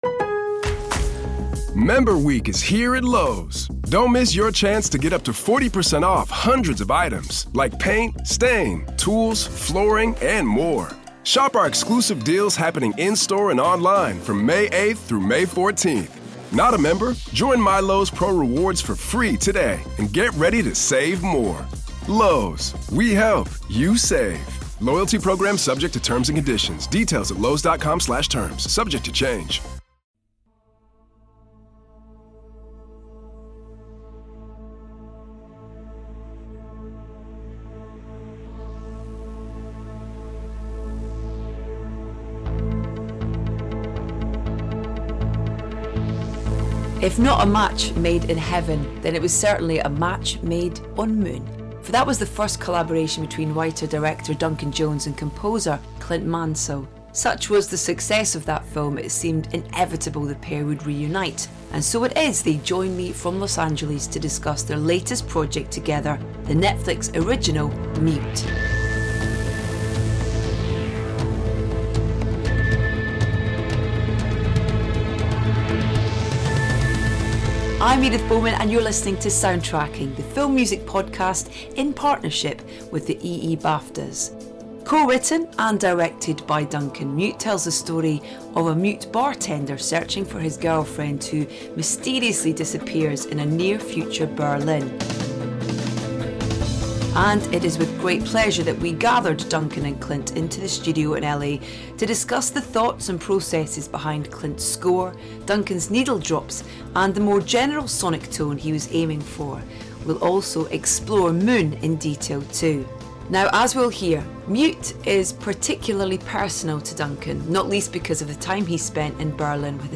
We have a treat for you on this week's episode of Soundtracking in partnership with the EE BAFTAs, as Duncan and Clint join Edith from LA